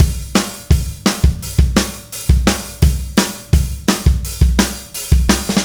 Indie Pop Beat 03 Fill.wav